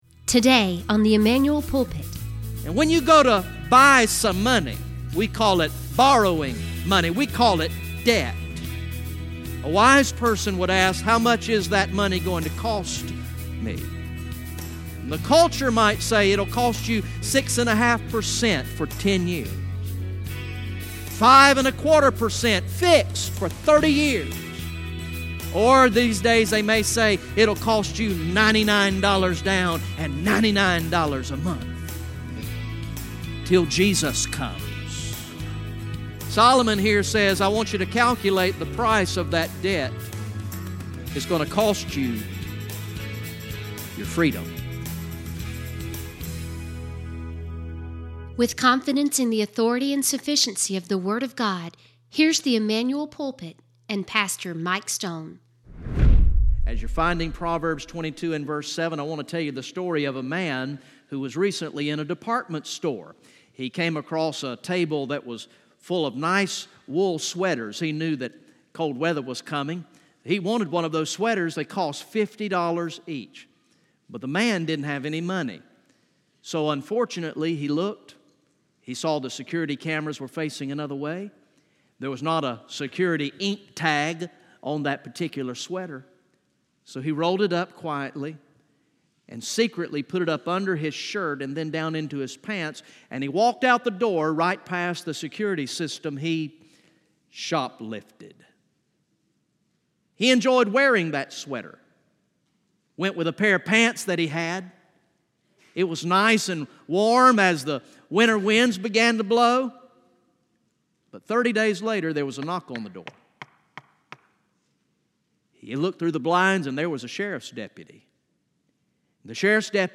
From the morning worship service on Sunday, November 4, 2018